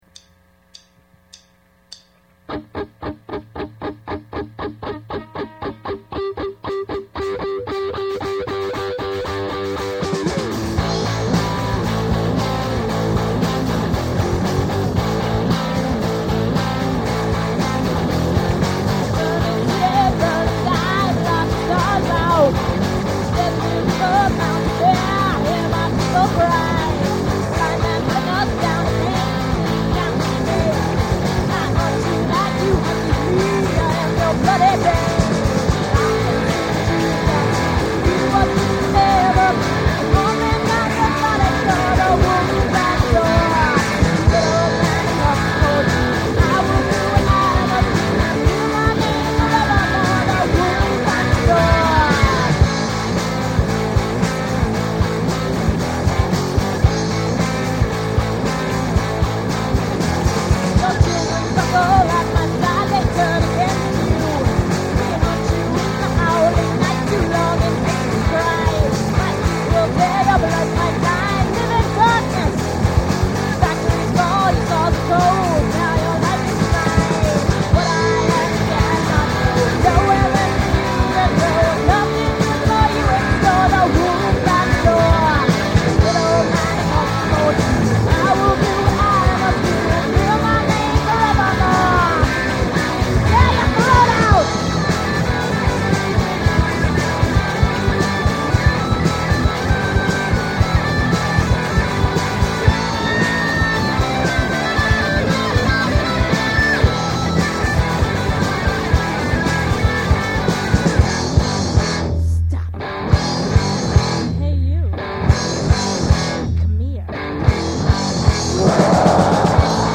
guitars, bass.
drums
vocals
synths & samples